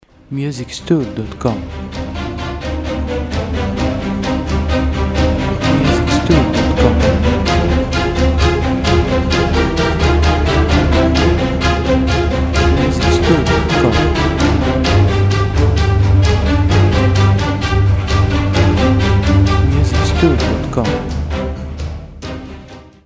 • Type : Instrumental / Audio Track
• Lyrics : No
• Bpm : Allegro
• Genre : Action / Battle Soundtrack